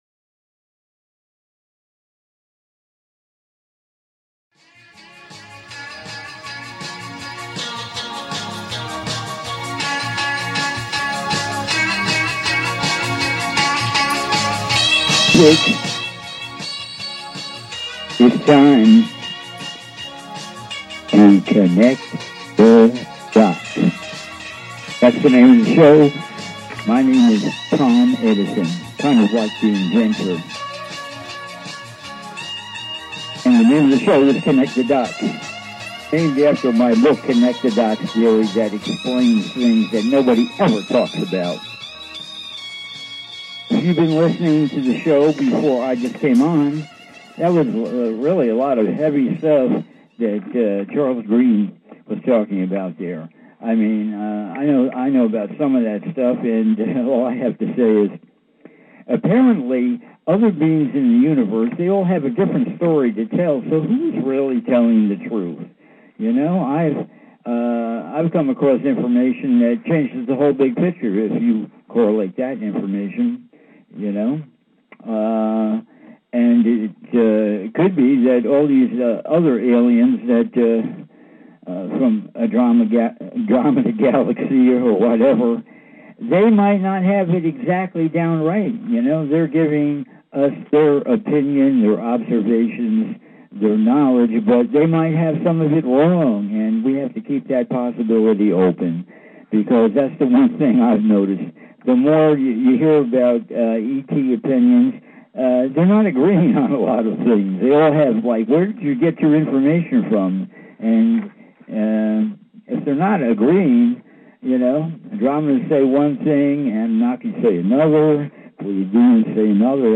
"CONNECT THE DOTS" is a call in radio talk show, where I share my knowledge of the metaphysical, plus ongoing conspiracies, plus the evolution of planet earth - spiritual info - et involvement - politics - crystals - etc.